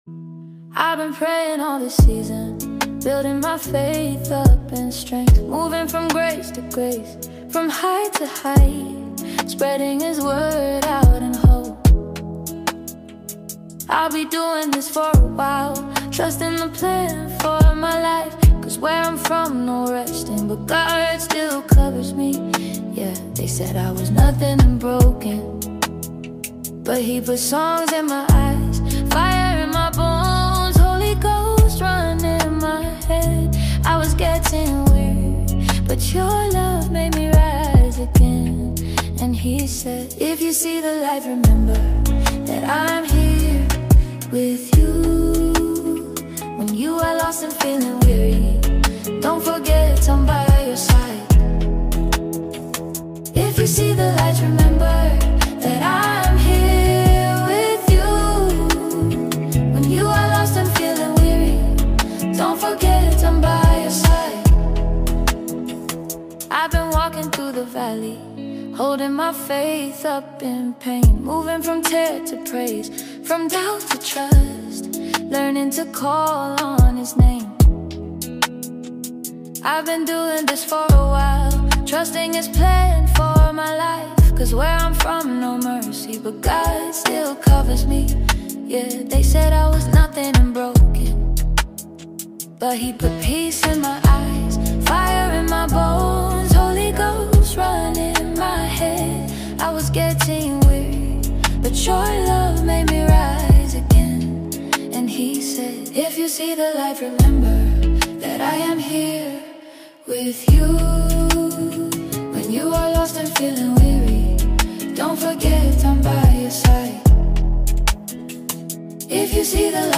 Afrobeat music is perfectly blended in the tracks